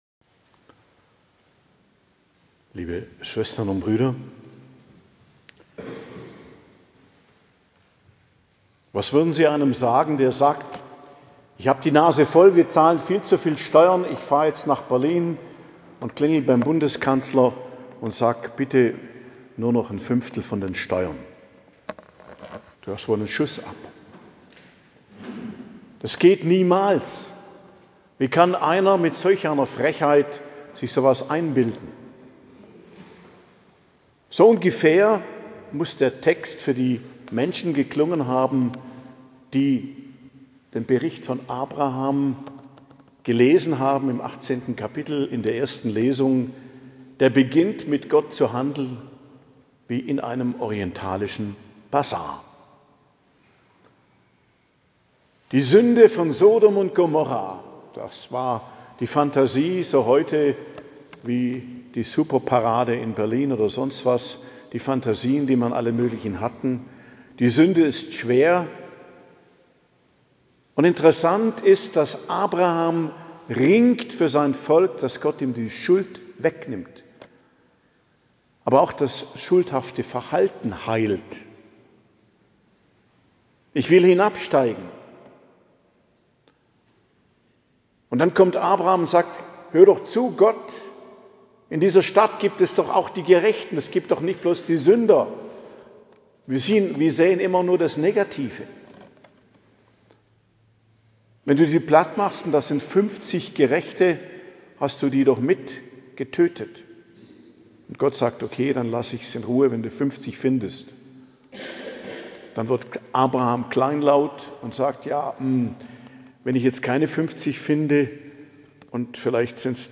Predigt zum Patrozinium Hll Anna & Joachim, 27.07.2025 ~ Geistliches Zentrum Kloster Heiligkreuztal Podcast